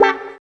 137 GTR 11-L.wav